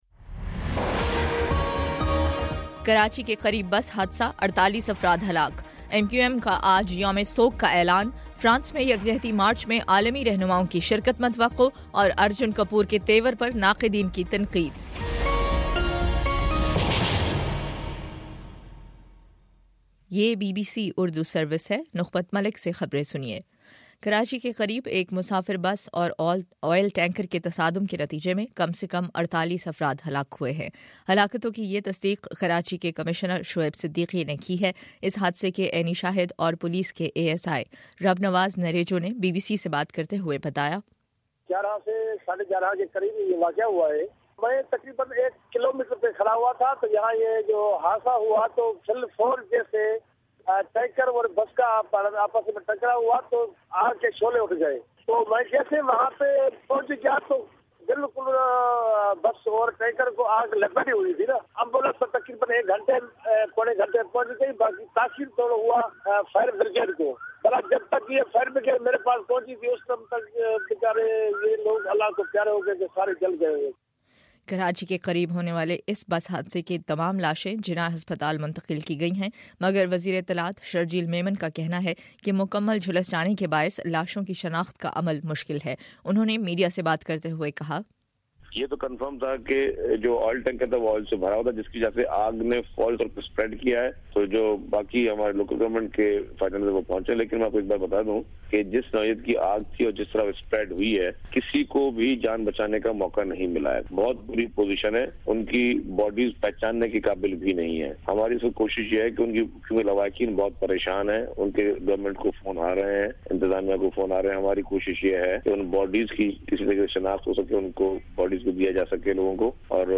جنوری 11: صبح نو بجے کا نیوز بُلیٹن